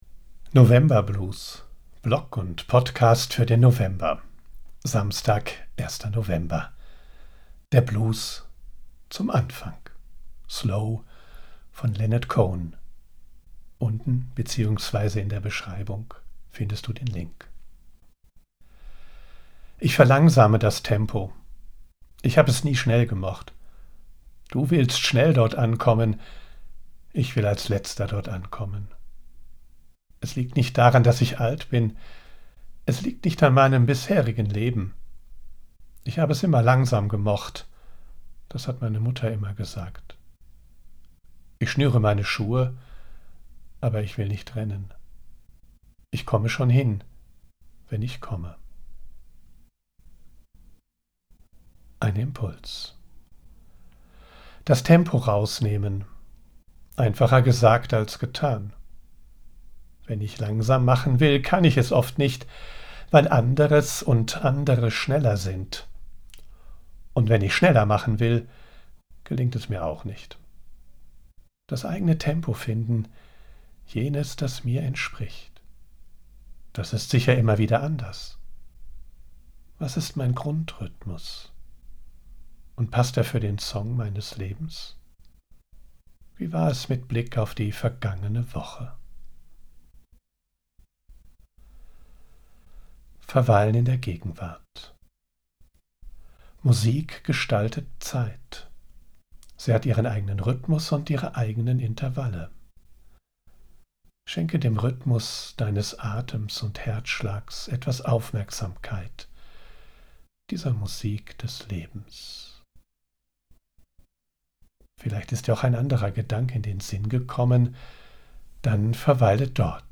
00:00:00 Der Blues zum Anfang